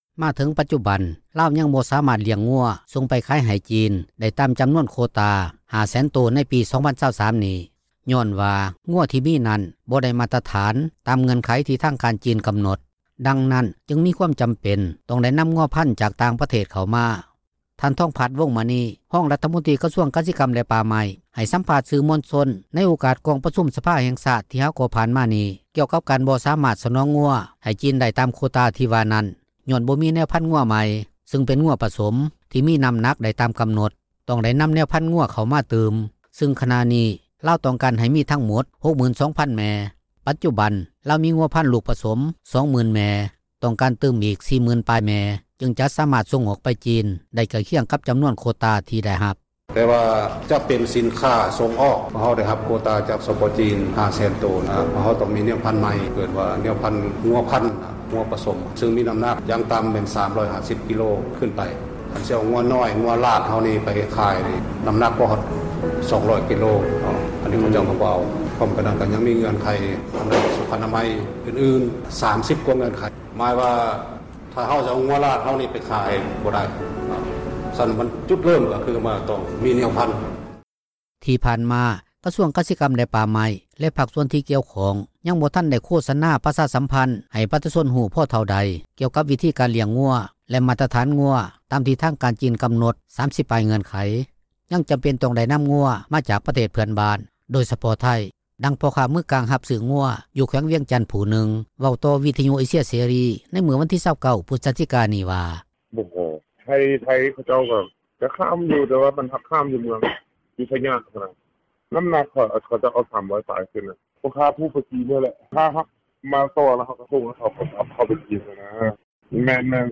ດັ່ງຄົນລ້ຽງງົວຢູ່ແຂວງບໍຣິຄໍາໄຊຜູ້ນຶ່ງ ເວົ້າຕໍ່ວິທຍຸເອເຊັຽເສຣີ ໃນມື້ດຽວກັນນີ້ວ່າ:
ດັ່ງຜູ້ລ້ຽງງົວລາດ ຢູ່ເມືອງວັງວຽງ ແຂວງວຽງຈັນ ຄົນນຶ່ງເວົ້າວ່າ:
ດັ່ງນາຍໜ້າຂາຍທີ່ດິນ ຢູ່ເມືອງໄຊທານີ ນະຄອນຫລວງວຽງຈັນຜູ້ນຶ່ງເວົ້າວ່າ: